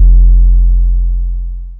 808 - 2K.wav